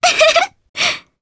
Mirianne-Vox_Happy2_jp.wav